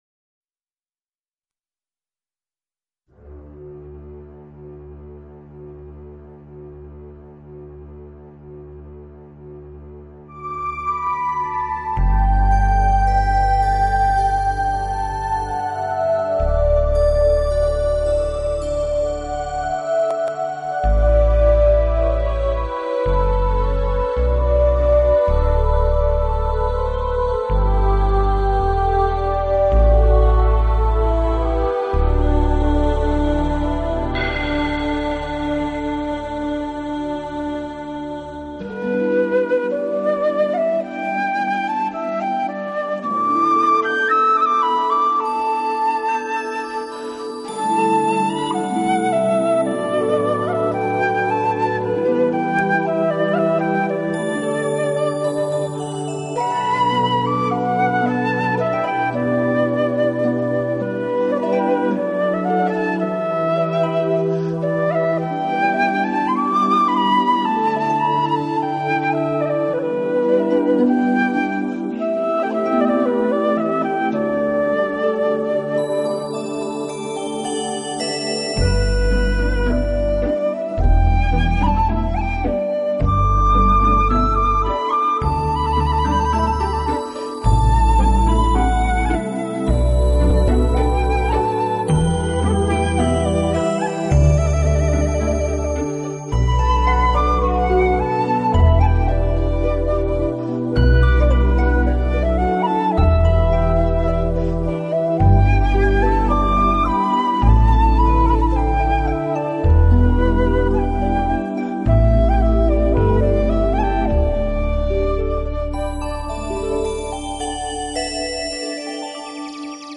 专辑歌手：纯音乐
洞箫，江南，阴柔的洞箫。